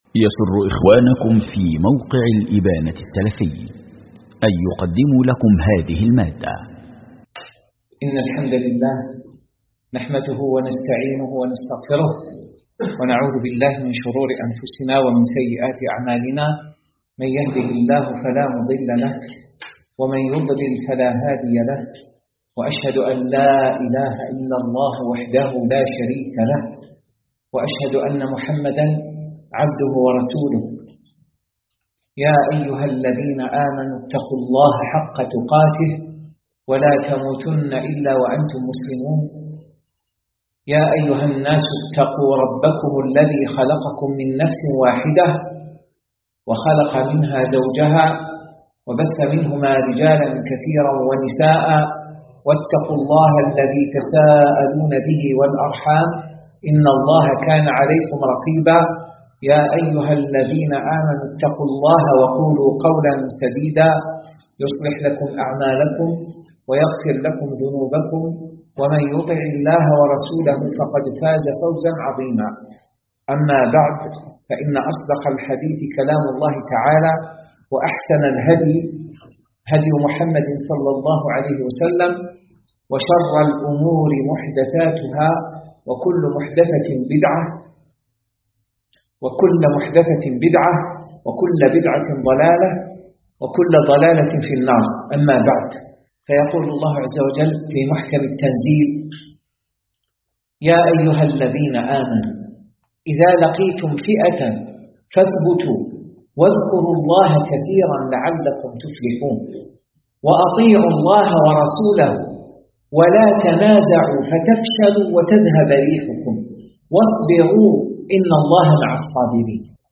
محاضرة
مواعظ ورقائق